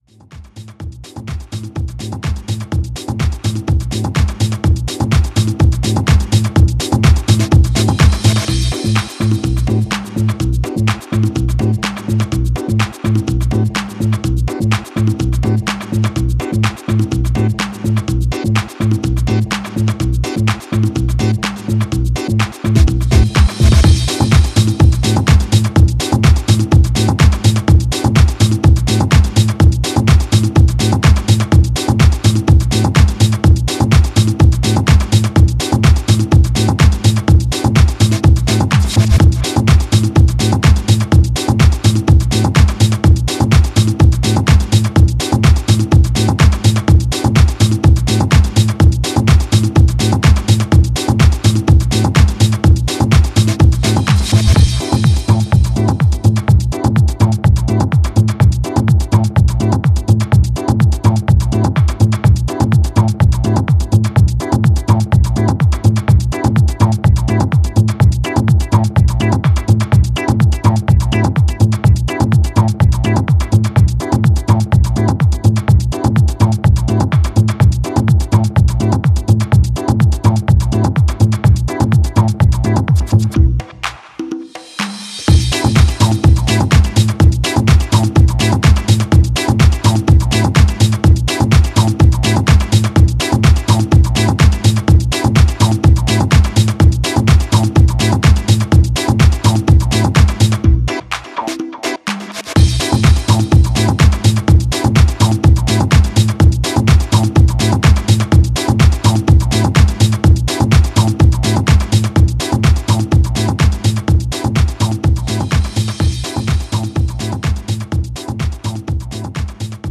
Tracks : 10 House Music Tracks